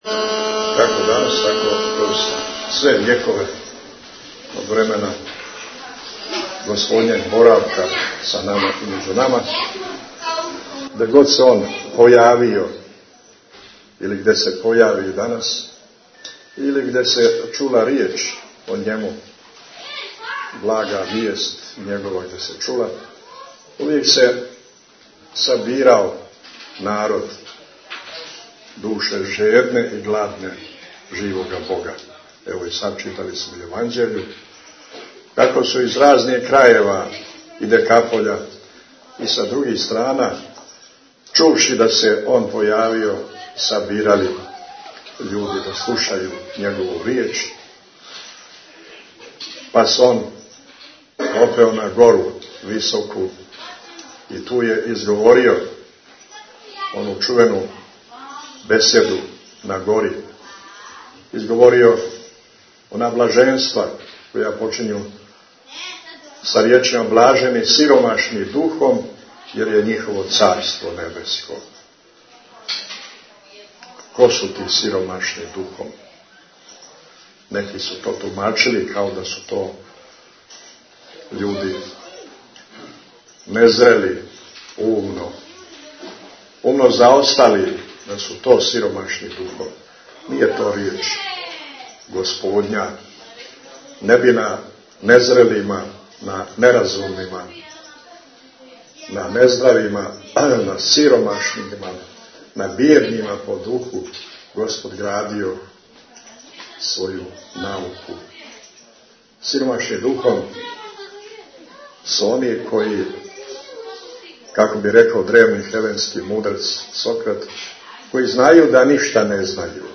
Митрополит Амфилохије на Духовски уторак служио Литургију у манастиру Стањевићи | Радио Светигора
Tagged: Бесједе
Владика је Литургију служио поводом Тројичиндана - храмовске славе ове древне светиње. У литургијској проповиједи Господин Митрополит је рекао да је Бог ушао у овај свијет сишавши с небеса и постао као један од нас.